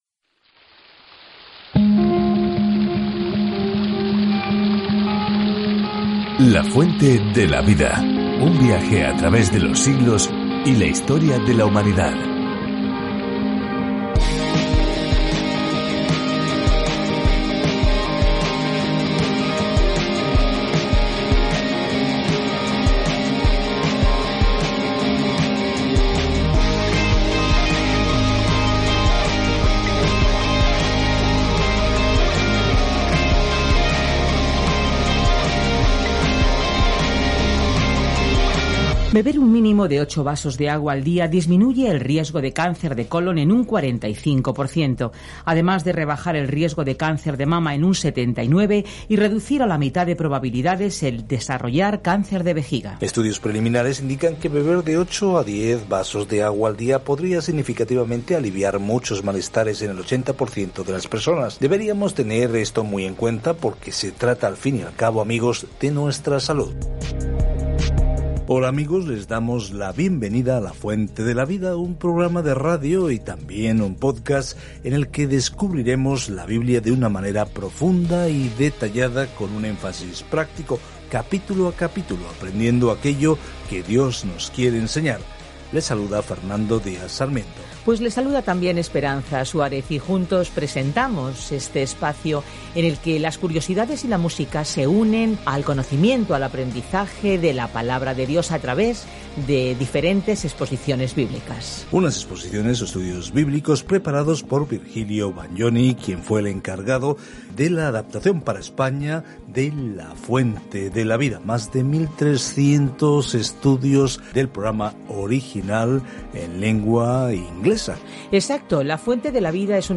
Escritura GÉNESIS 1:26-31 GÉNESIS 2:1-3 Día 2 Iniciar plan Día 4 Acerca de este Plan Aquí es donde comienza todo: el universo, el sol y la luna, las personas, las relaciones, el pecado, todo. Viaja diariamente a través de Génesis mientras escuchas el estudio de audio y lees versículos seleccionados de la palabra de Dios.